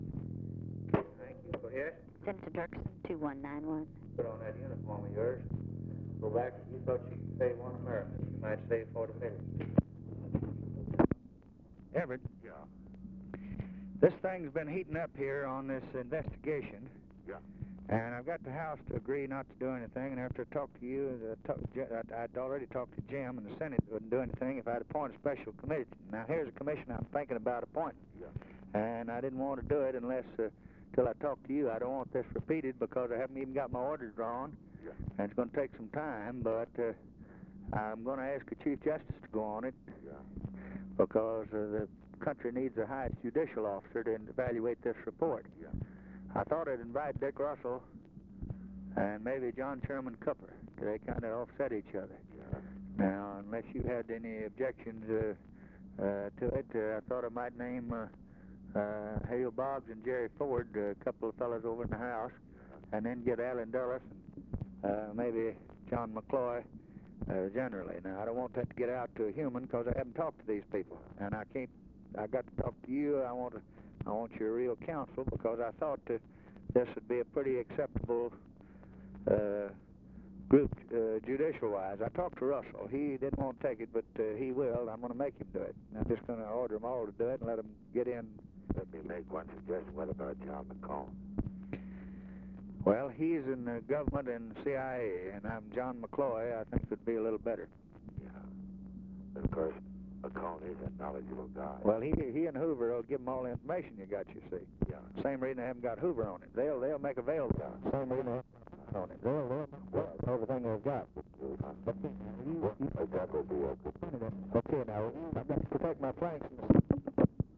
Conversation with EVERETT DIRKSEN, November 29, 1963
Secret White House Tapes